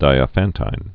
(dīə-făntīn, -tĭn)